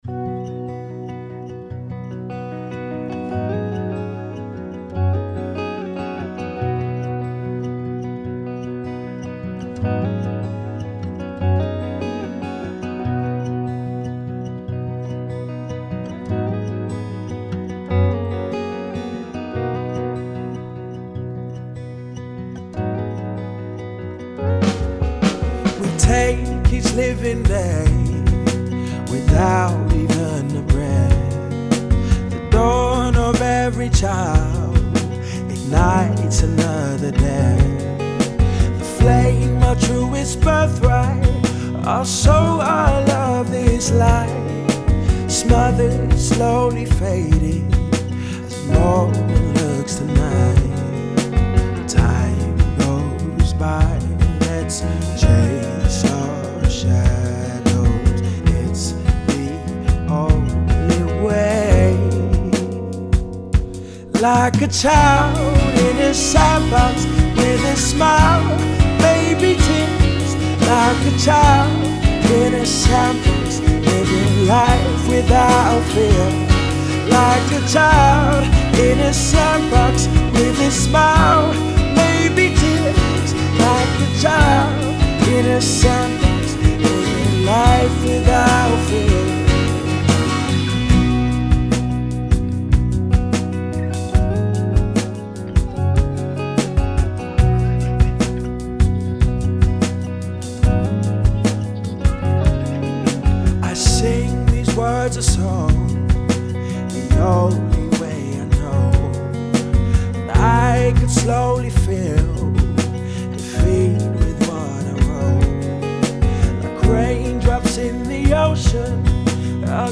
voice has very strong emotive abilities